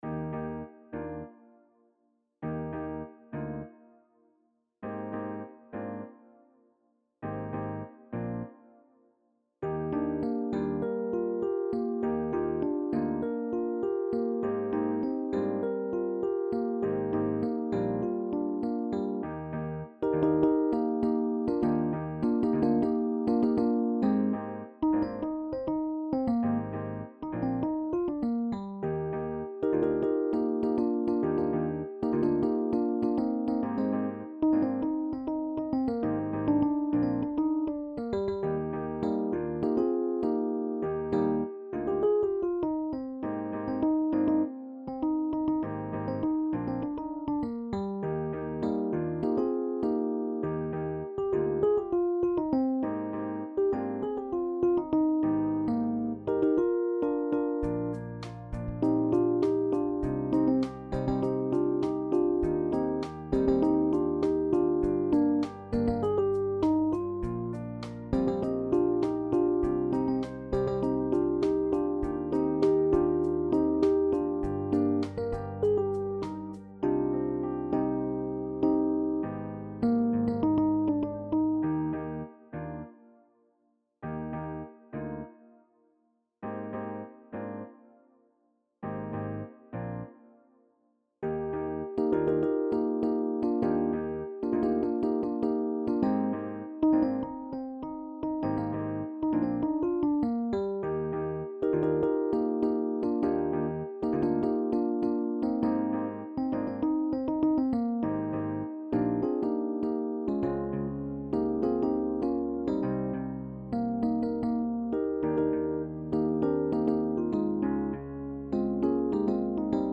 SSAA met piano